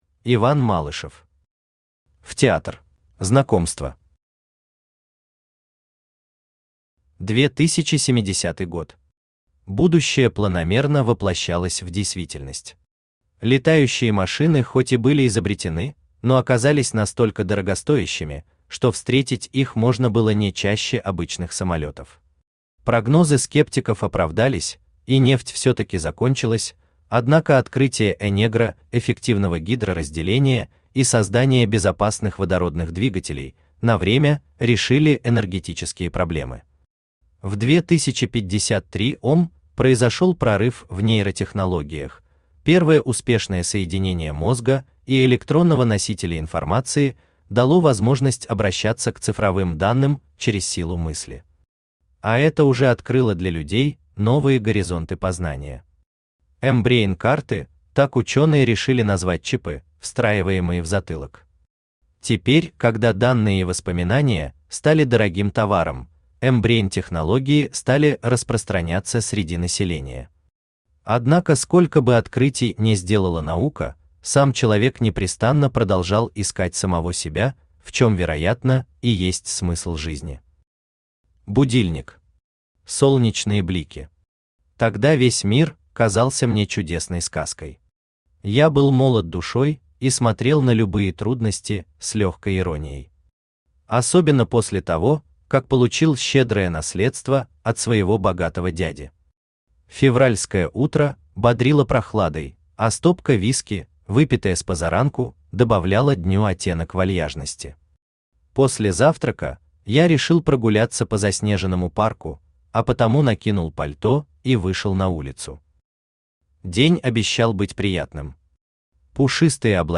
Аудиокнига В-Театр | Библиотека аудиокниг
Aудиокнига В-Театр Автор Иван Малышев Читает аудиокнигу Авточтец ЛитРес.